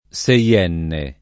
seienne [ S e L$ nne ]